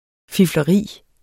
Udtale [ fiflʌˈʁiˀ ]